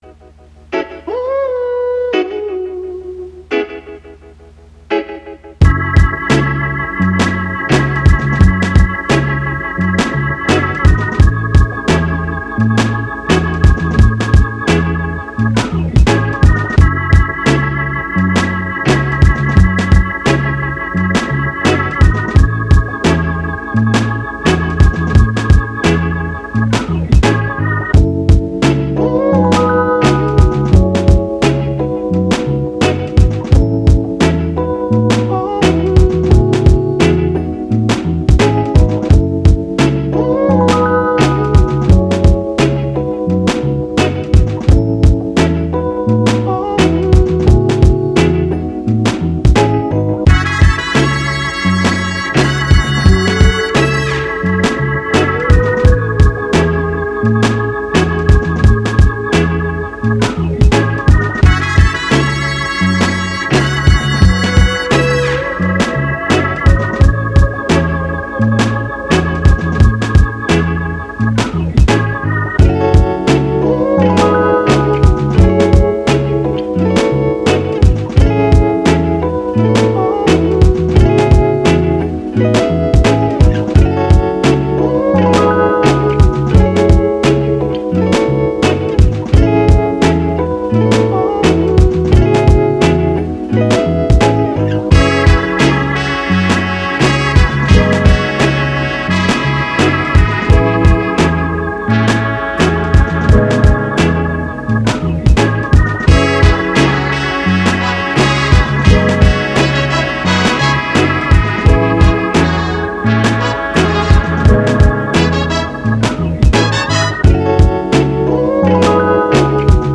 Soul, Chill, Vintage, Vibe